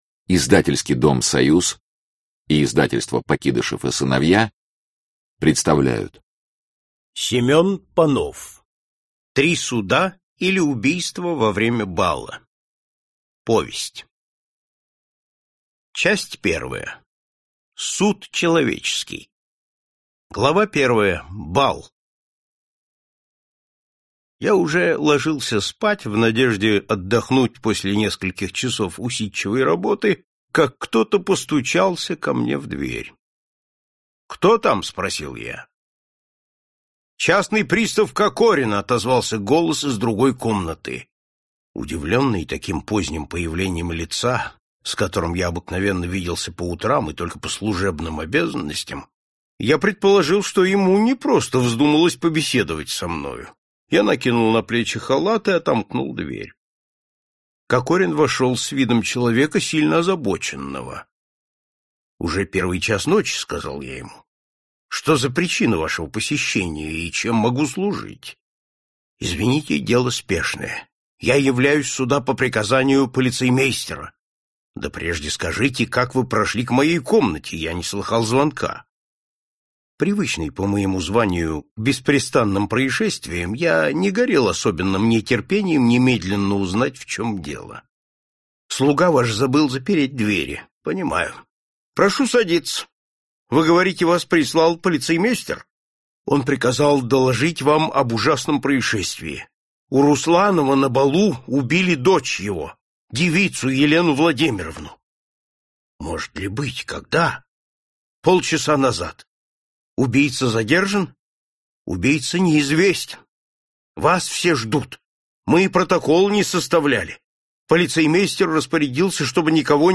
Аудиокнига Три суда или убийство во время бала | Библиотека аудиокниг